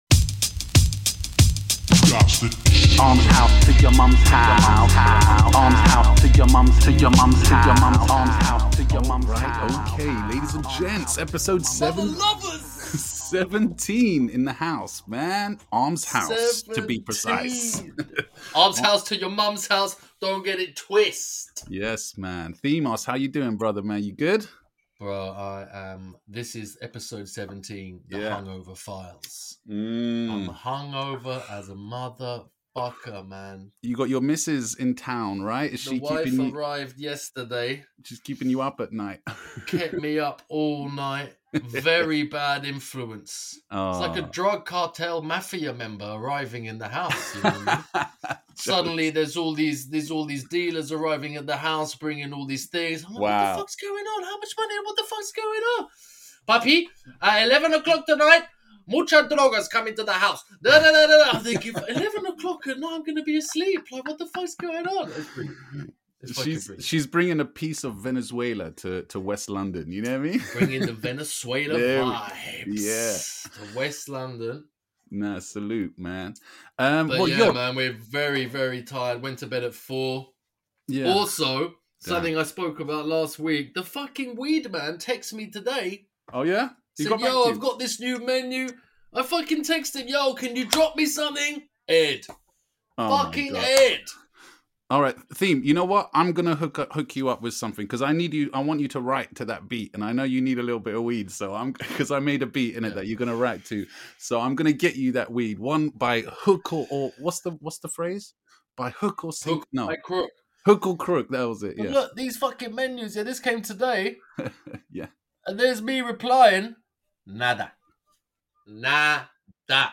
This episode starts off a bit deep, but then they listen to some tunes and critique some of the Graff sent in by you lot 😂👍🏽 Enjoy!